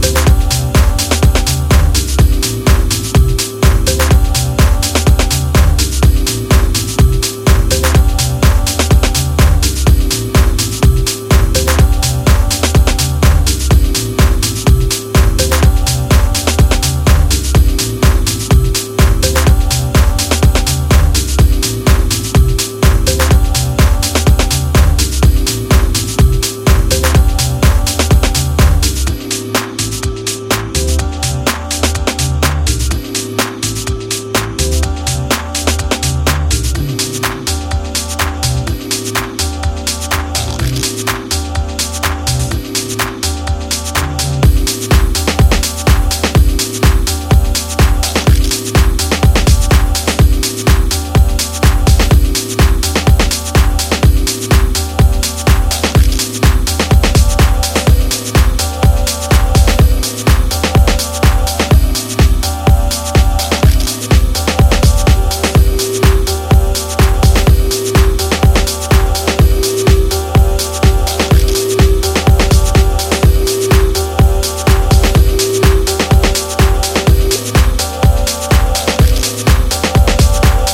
underground jackin’ groover